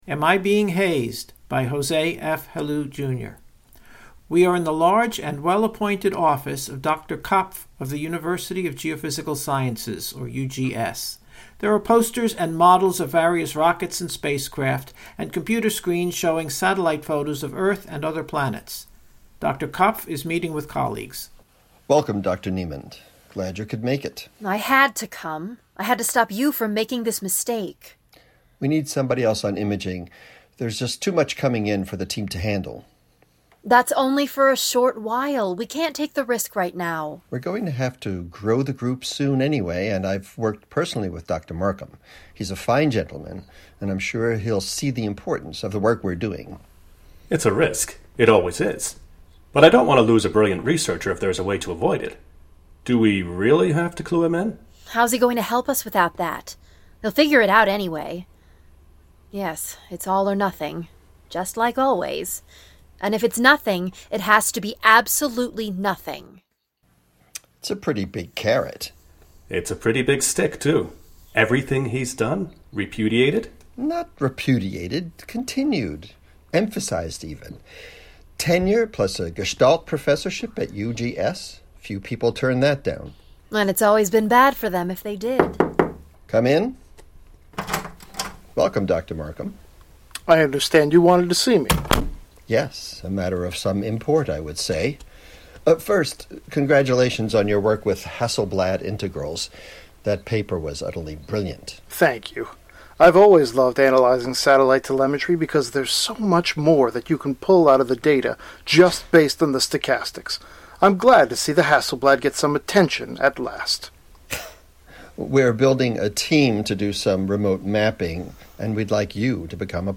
Reading:
Individual performers voiced their parts separately, offline, and the tracks were edited together into a single performance. The process was iterated three times, ultimately allowing the actors to respond to cues from the other actors who were themselves responding to what other actors were saying.